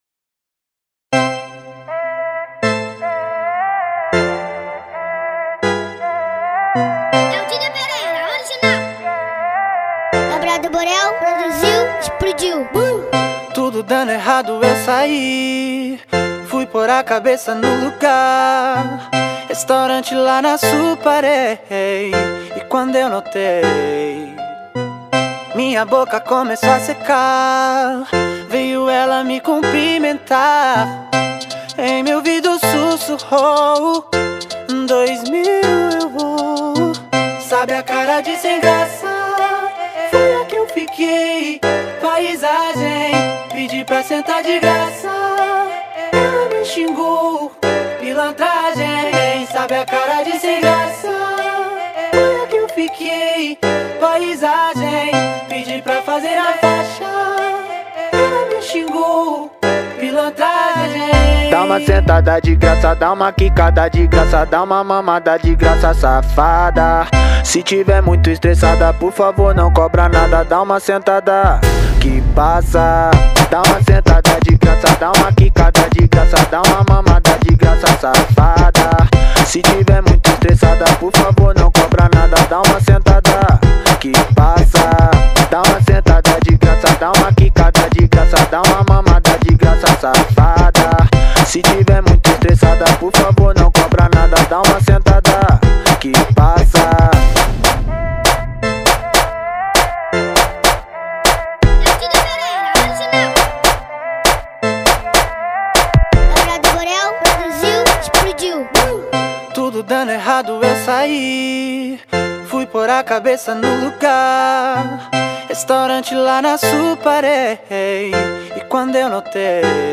2024-02-16 23:24:17 Gênero: Funk Views